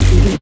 Key-bass_67.3.3.wav